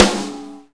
normal-hitclap2.wav